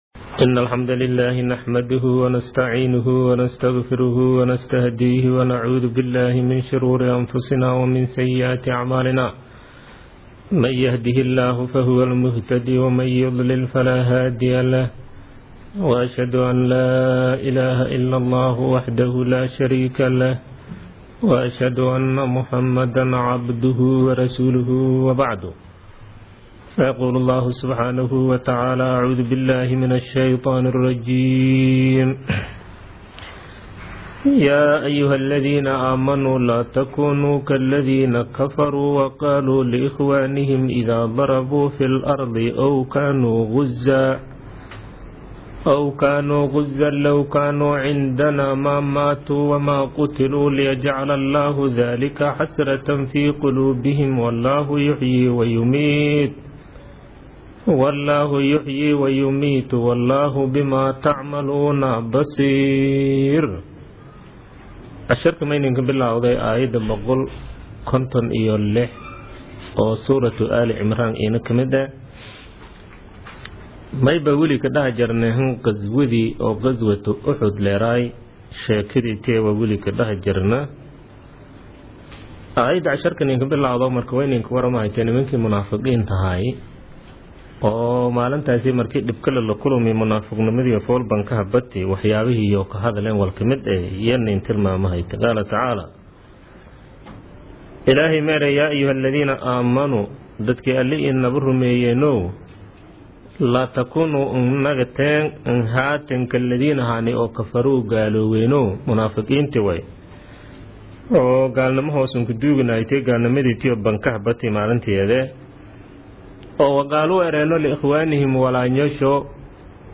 Casharka Tafsiirka Maay 54aad